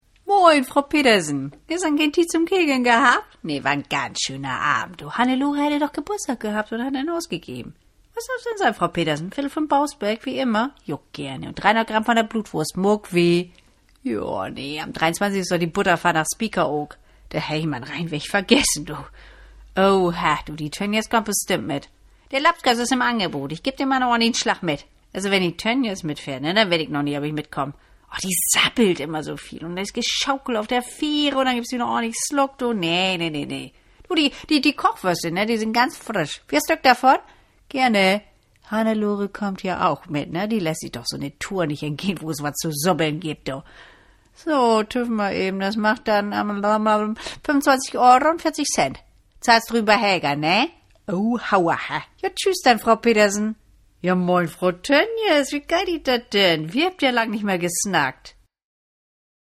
Schauspielerin, Sprecherin
Sprechprobe: Werbung (Muttersprache):